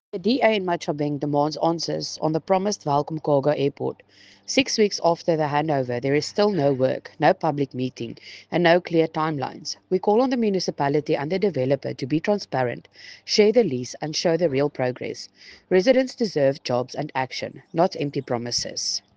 Afrikaans soundbites by Cllr Estelle Dansey and